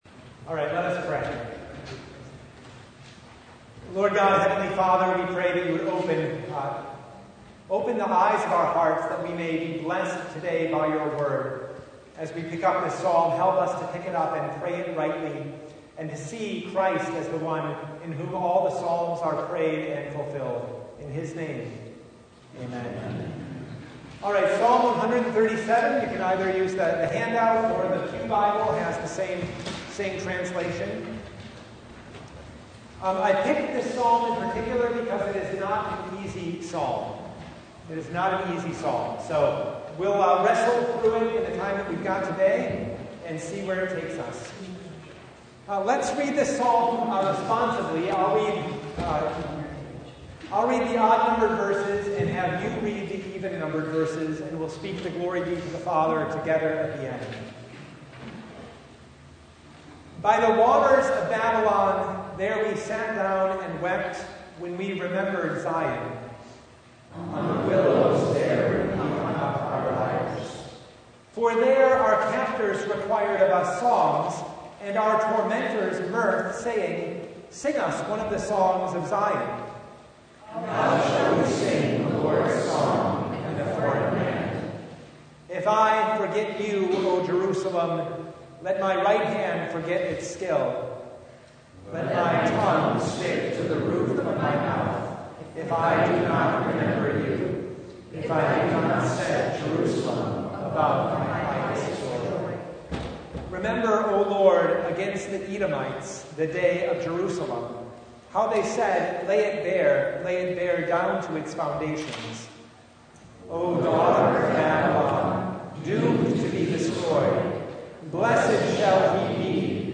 Psalm 137 Service Type: Bible Study By the waters of Babylon